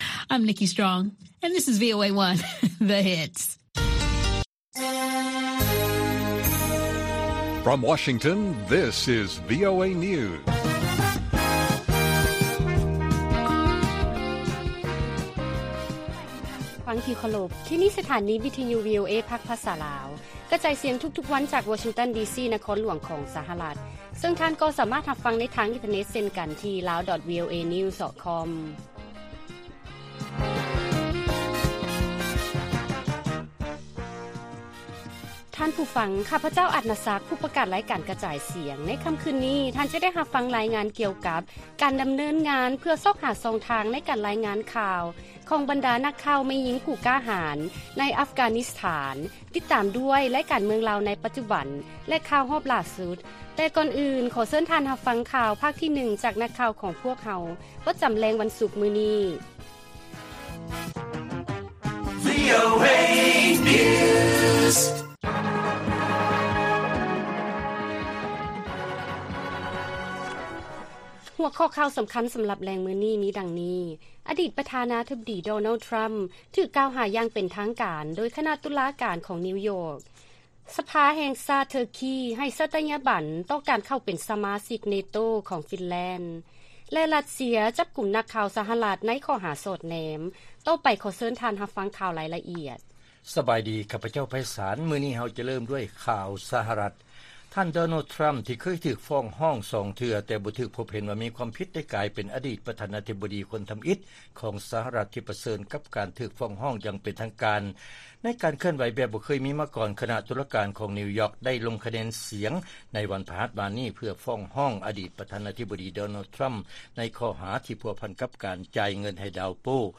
ລາຍການກະຈາຍສຽງຂອງວີໂອເອ ລາວ: ອະດີດປະທານາທິບໍດີດໍໂນລ ທຣຳ ຖືກກ່າວຫາຢ່າງເປັນທາງການ ໂດຍຄະນະຕຸລາການ ຂອງນິວຢອກ